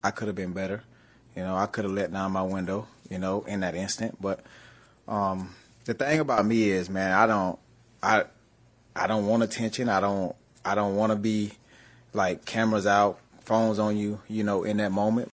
The star wide receiver spoke at a news conference earlier today about the incident.
Hill expanded on his reaction to the detainment in a local news conference.
Dolphins-Presser-I-Could-Have-Been-Better.mp3